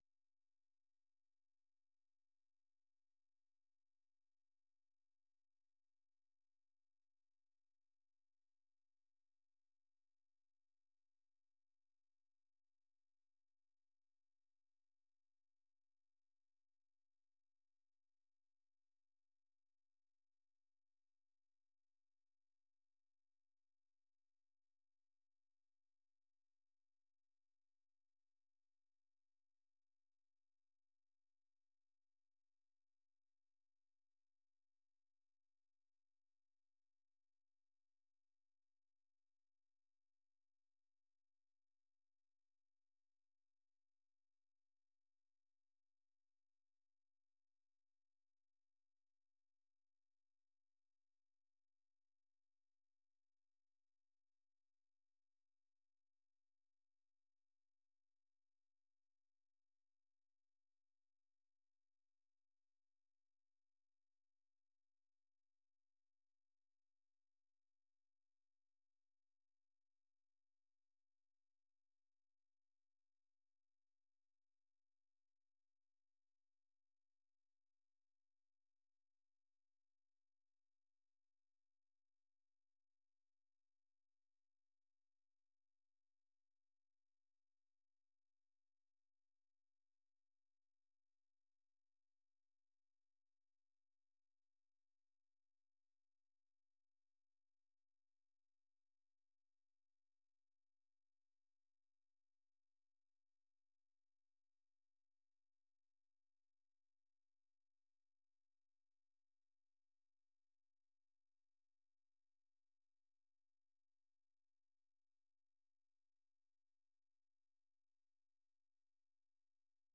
Listen Live - 생방송 듣기 - VOA 한국어
The URL has been copied to your clipboard 페이스북으로 공유하기 트위터로 공유하기 No media source currently available 0:00 0:59:59 0:00 생방송 여기는 워싱턴입니다 생방송 여기는 워싱턴입니다 공유 생방송 여기는 워싱턴입니다 share 세계 뉴스와 함께 미국의 모든 것을 소개하는 '생방송 여기는 워싱턴입니다', 아침 방송입니다.